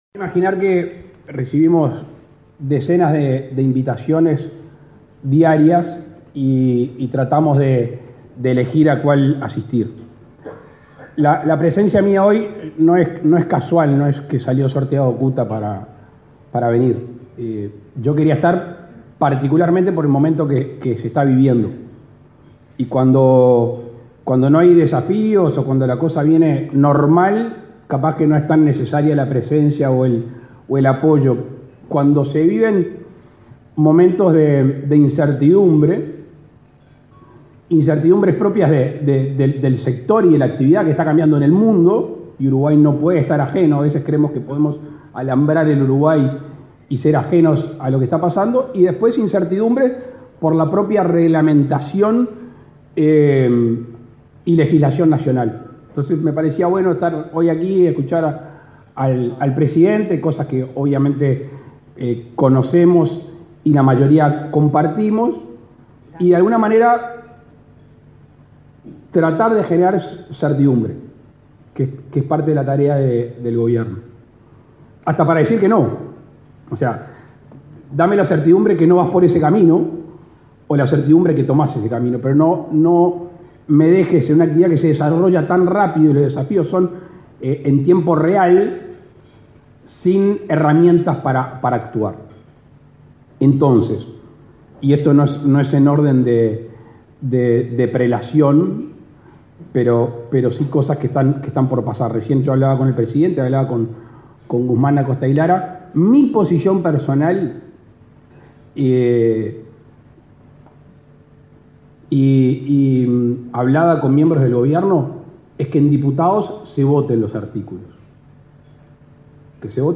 Palabras del presidente de la República, Luis Lacalle Pou
Con la presencia del presidente de la República, Luis Lacalle Pou, la Cámara Uruguaya de Televisión para Abonados celebró, este 11 de agosto, el 26.°